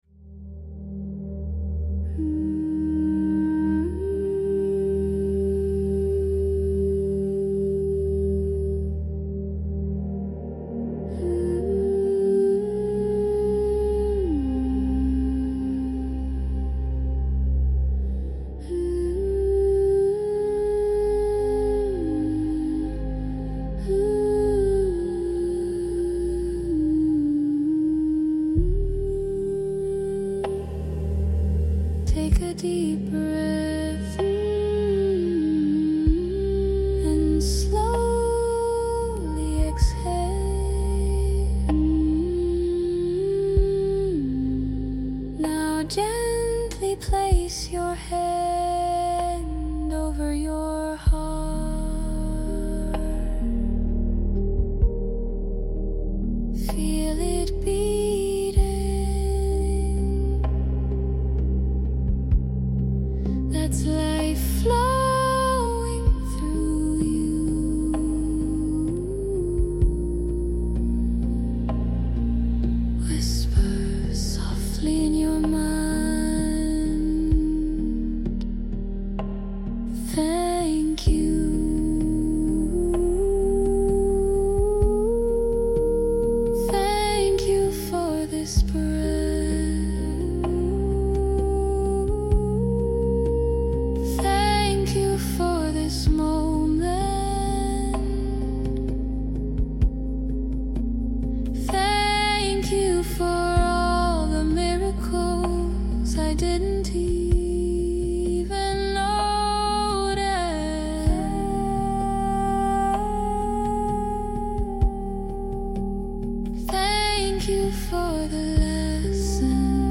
✨ Life-Changing Meditation ✨ Close your eyes, breathe in, and tune into the frequency of abundance. This guided session starts with deep gratitude and opens the gates to joy, healing, success, and divine wealth.